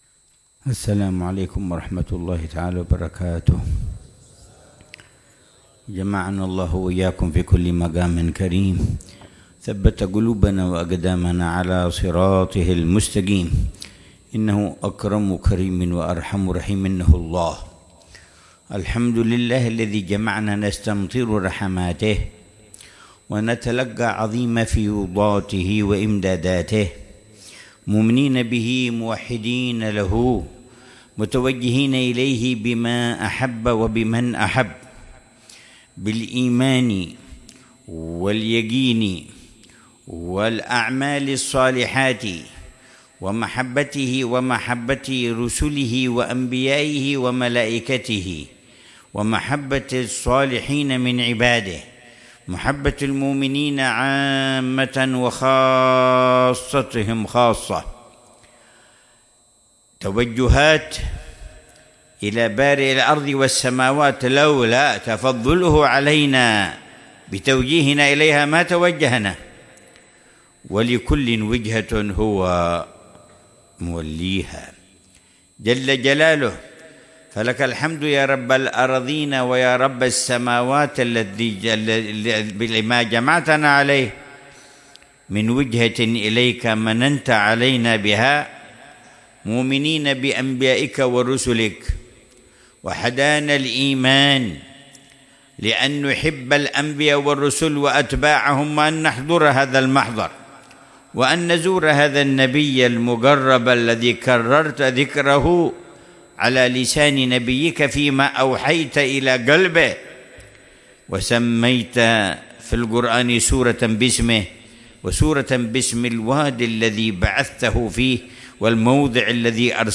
محاضرة العلامة الحبيب عمر بن محمد بن حفيظ في المجلس الأول من مجالس الدعوة إلى الله في شعب النبي هود عليه السلام، ضمن محاور ( تقويم الإيمان وتقويم السلوك) ، ليلة الأربعاء 6 شعبان 1446هـ بعنوان:&nbsp